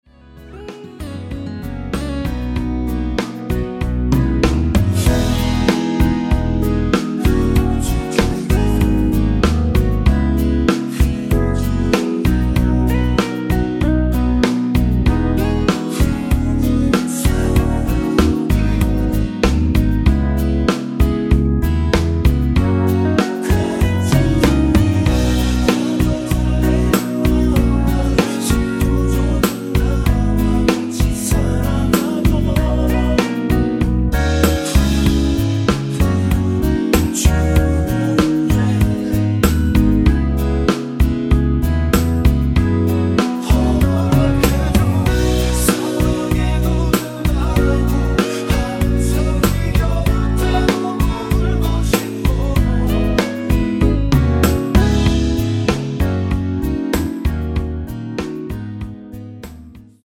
코러스 포함된 MR 입니다.
◈ 곡명 옆 (-1)은 반음 내림, (+1)은 반음 올림 입니다.
앞부분30초, 뒷부분30초씩 편집해서 올려 드리고 있습니다.
중간에 음이 끈어지고 다시 나오는 이유는
축가 MR